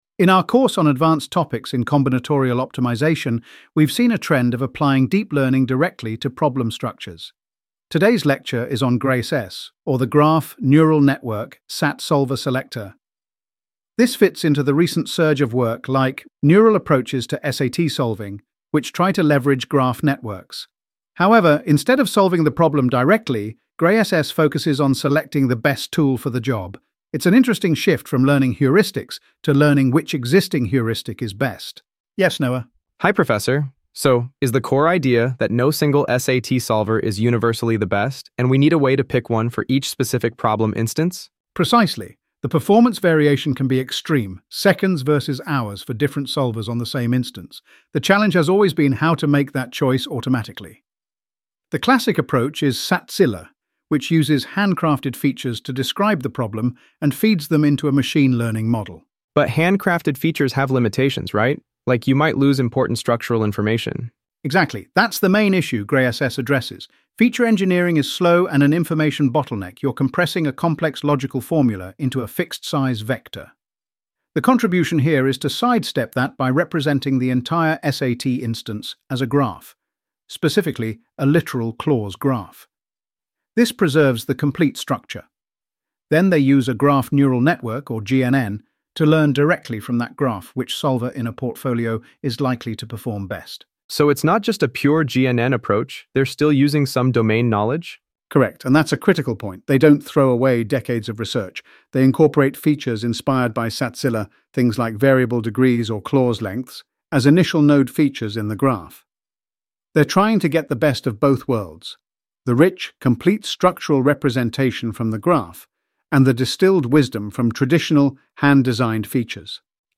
AI Audio Lecture + Q&A